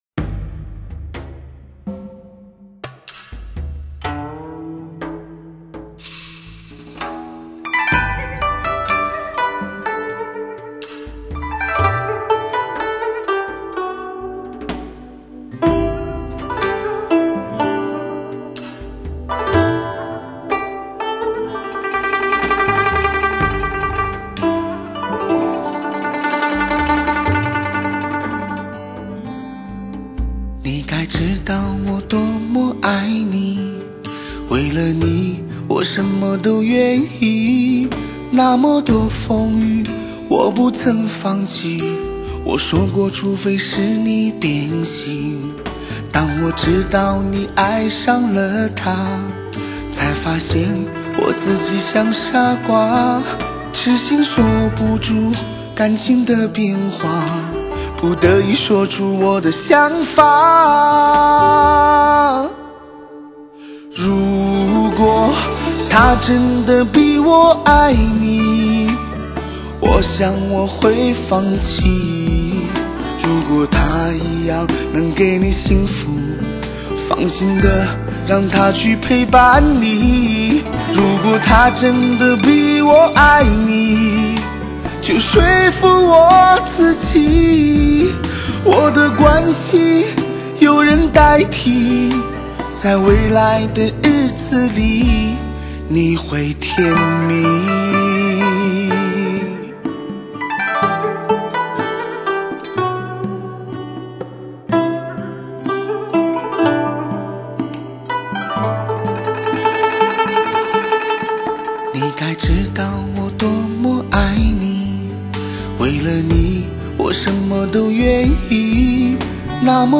精选最醇美的声音，最纯净的情感，顶级发烧，
现代情爱的伤感，现代情歌谱写现代爱情故事——